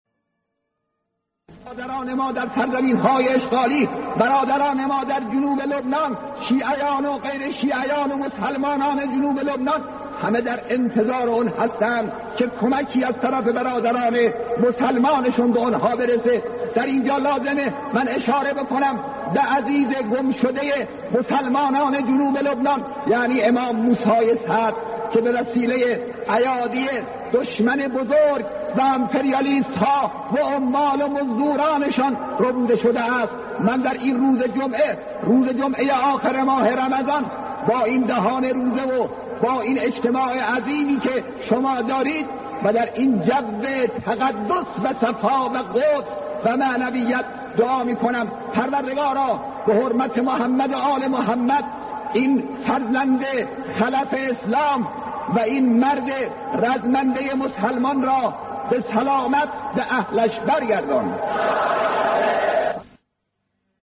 متن حضرت آیت‌الله خامنه‌ای در خطبه‌های نماز جمعه تهران در ۱۷ مرداد ۱۳۵۹ (روز قدس):